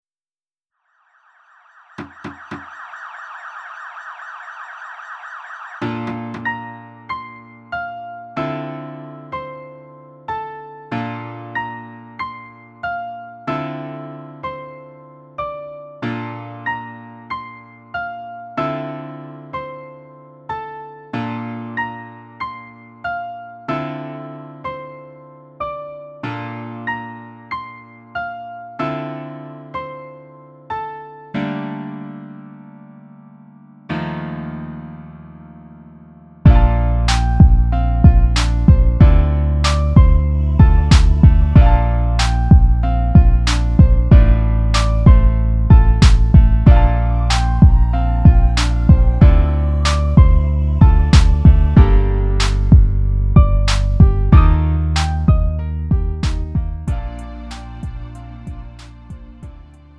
음정 원키 2:13
장르 구분 Lite MR